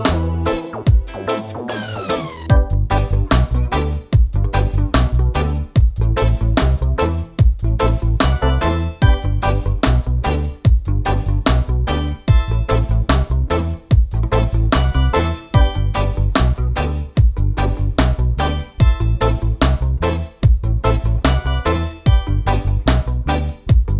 no Backing Vocals Reggae 4:25 Buy £1.50